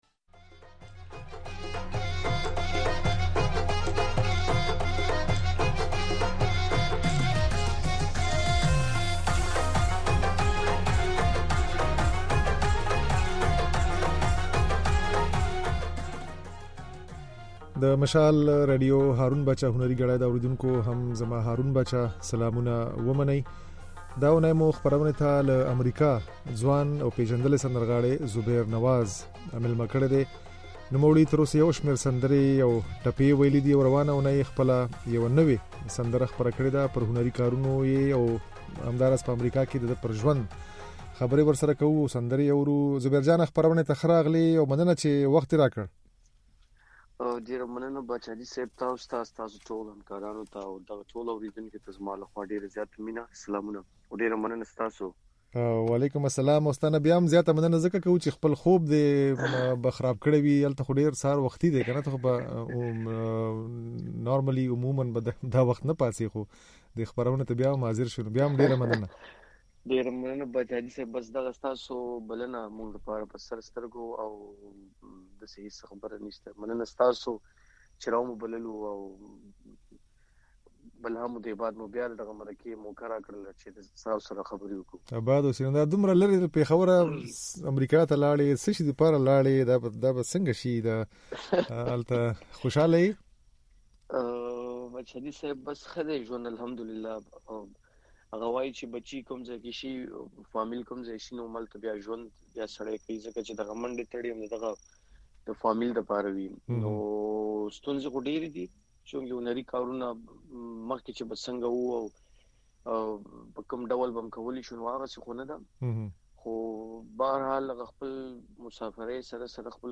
خپرونې مېلمه مو ځوان سندرغاړی